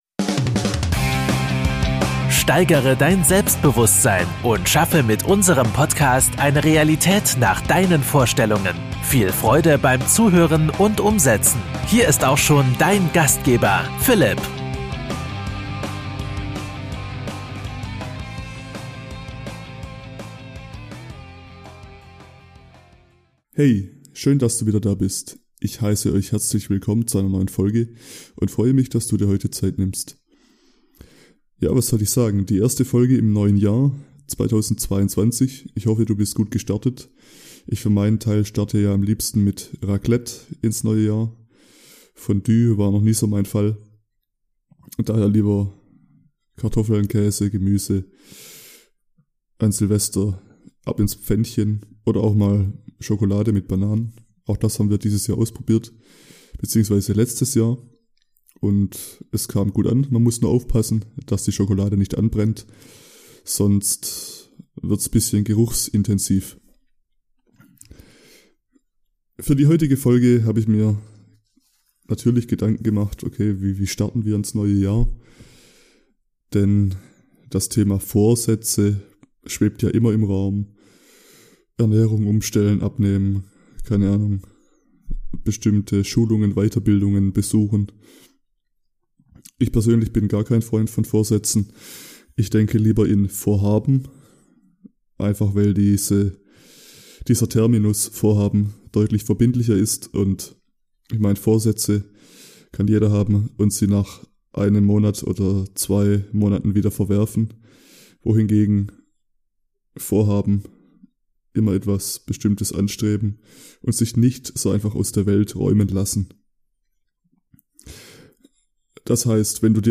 Zum Jahresbeginn erwartet Dich eine kleine Lesung.